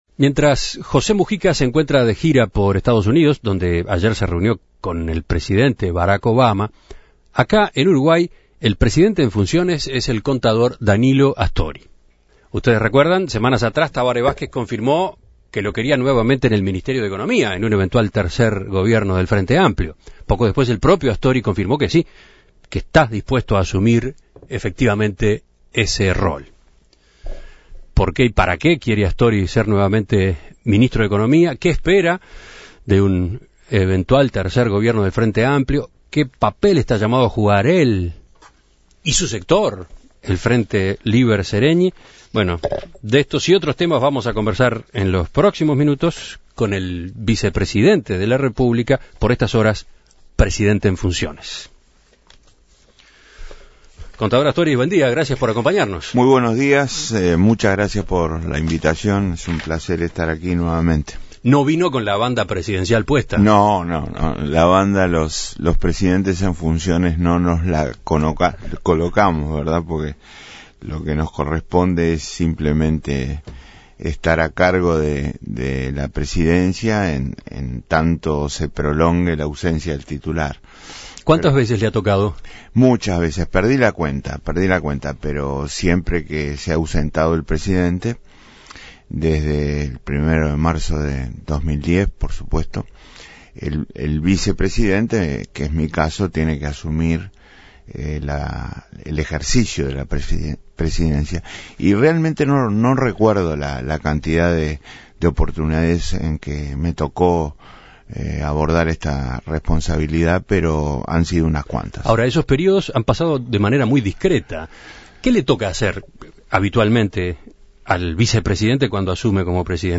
En Perspectiva dialogó con él para conocer sus tareas como presidente en funciones, cómo vio la gira de